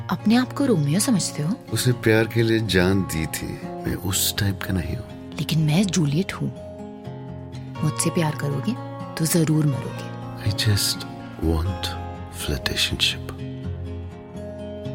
Category: Dailog